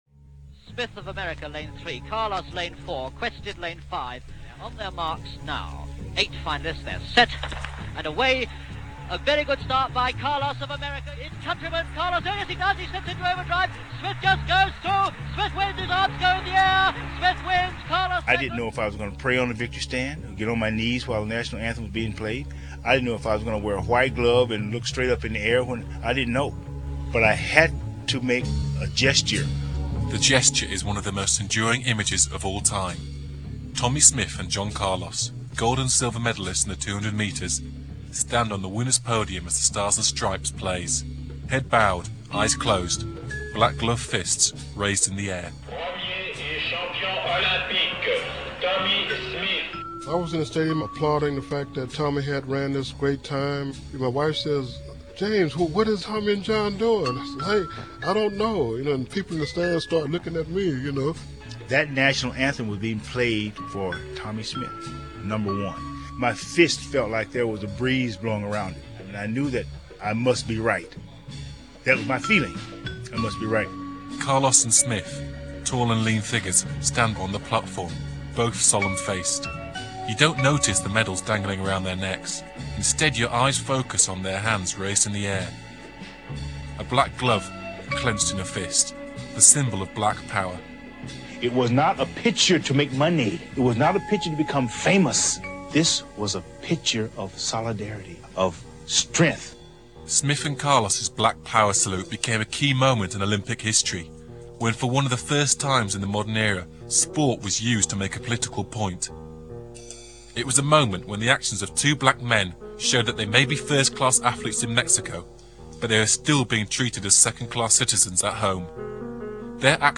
Black Power: 1968 - BBC Radio 1 Documentary on the Civil Rights struggle in the 60s and the pivotal year 1968, Interviews
BBC-Radio-1-Black-Power-Documentary.mp3